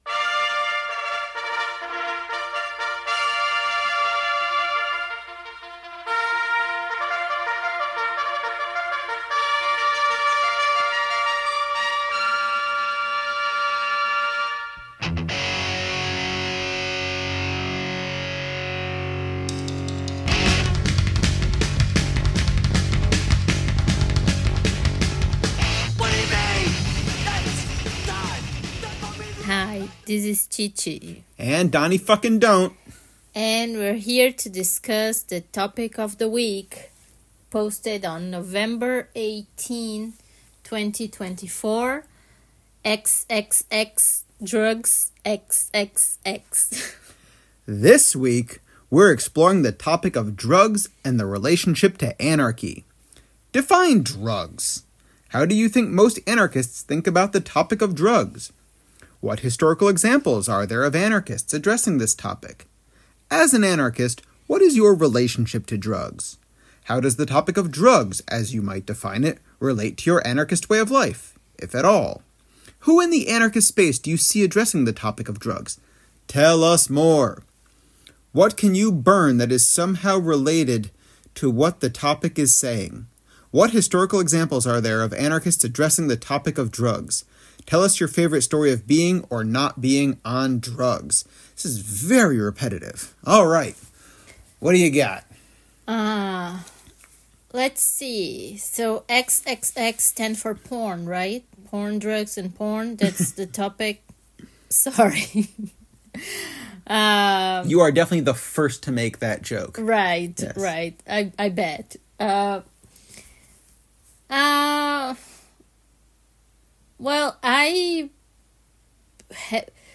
Drugs conversation